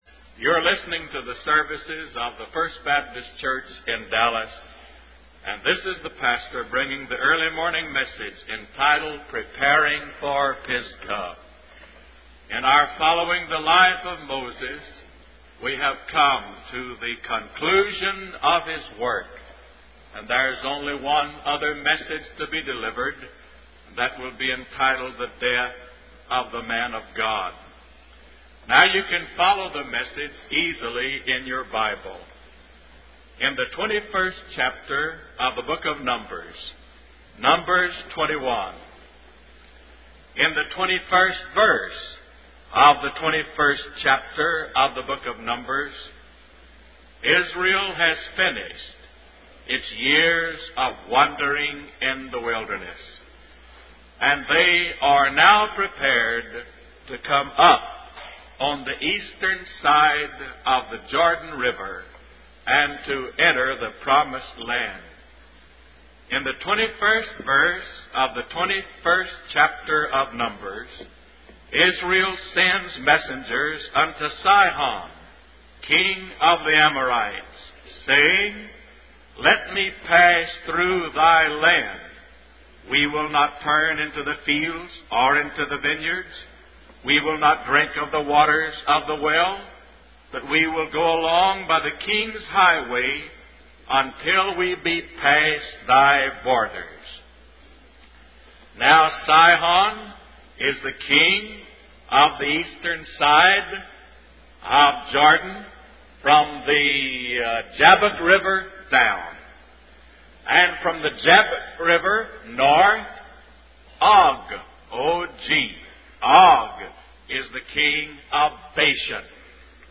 W. A. Criswell Sermon Library | Preparing for Pisgah